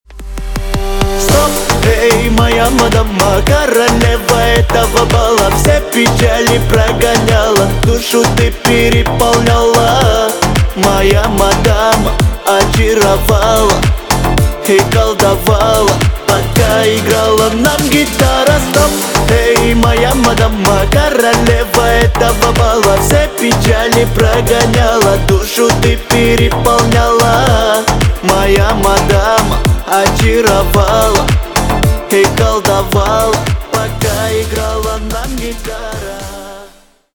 Поп Музыка # кавказские